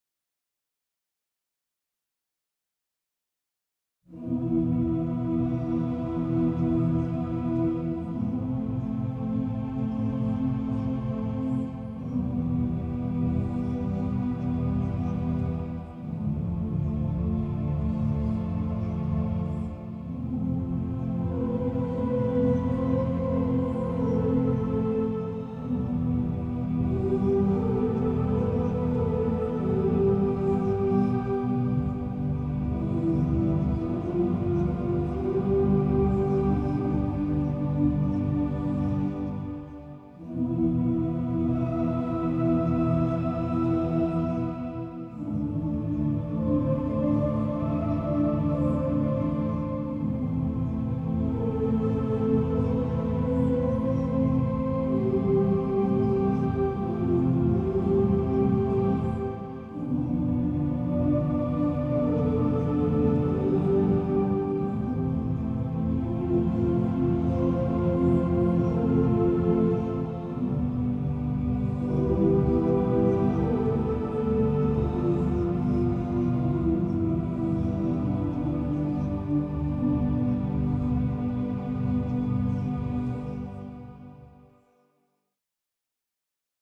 contrast-5-eigener-choir-synth.mp3